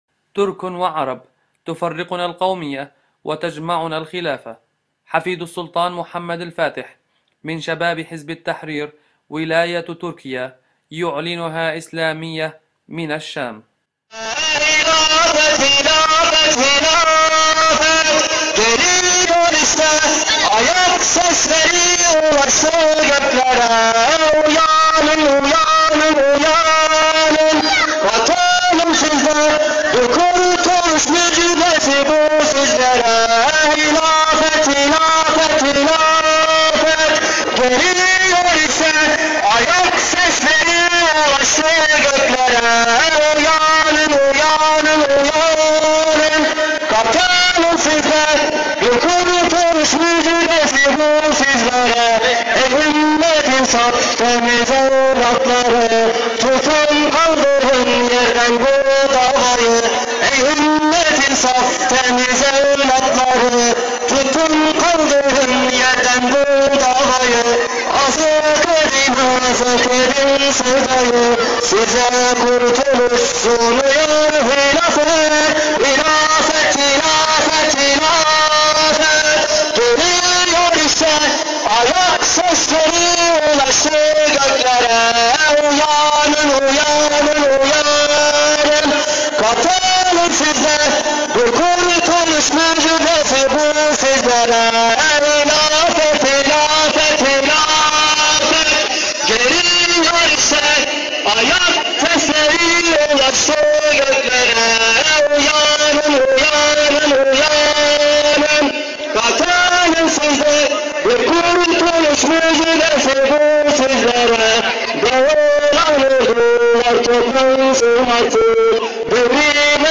نشيد